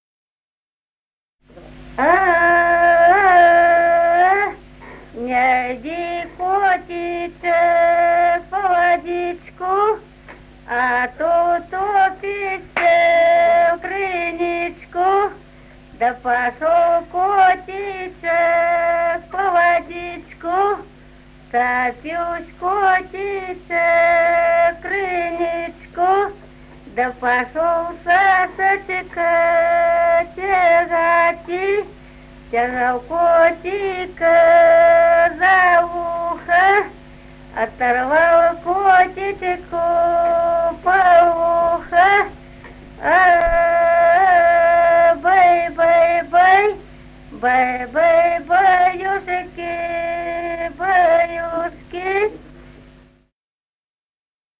Народные песни Стародубского района «Не йди, котичек», колыбельная.
с. Остроглядово.